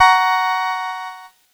Cheese Chord 12-F#3.wav